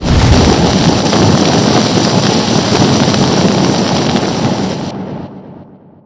rogue_anomaly_fire.ogg